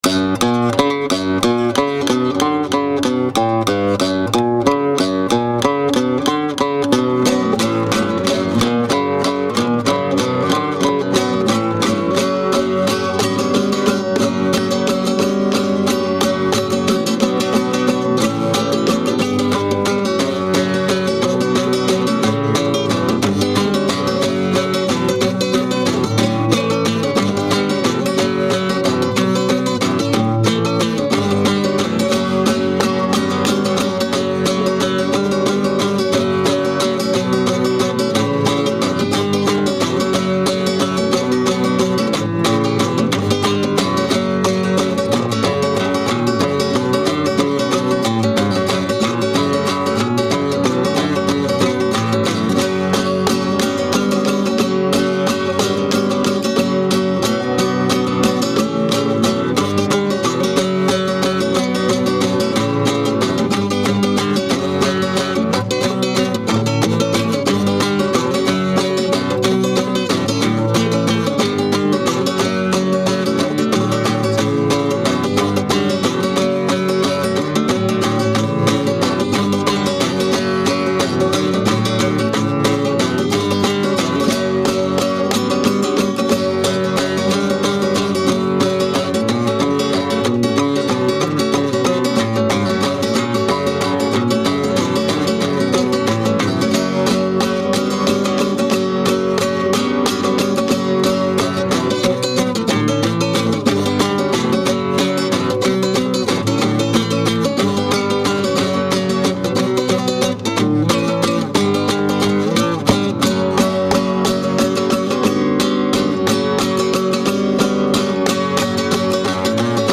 ferocious yet fluid flamencado
powerful and mystical rasgueado